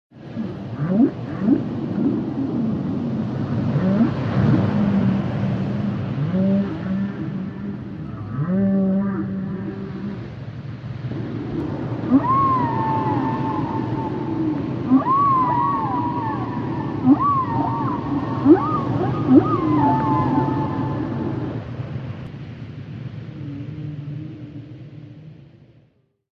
Category: Animal Ringtones